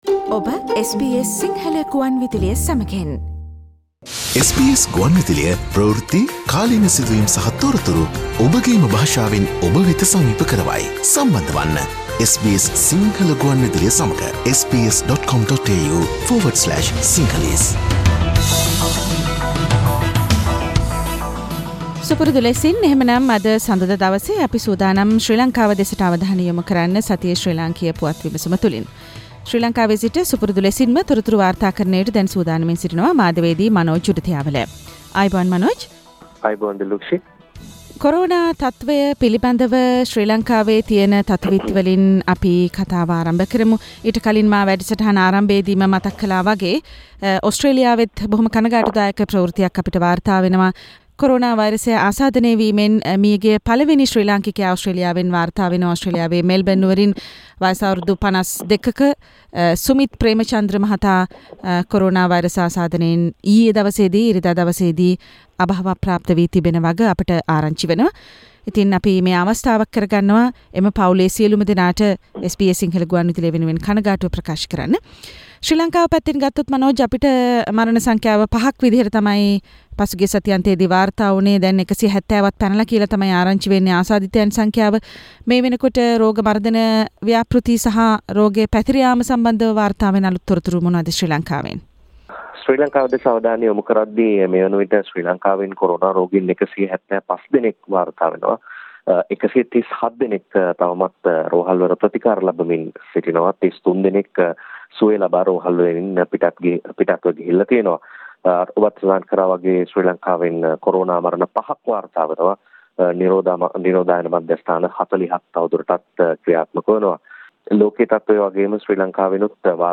weekly Sri Lankan news wrap Source: SBS Sinhala radio